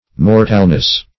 \Mor"tal*ness\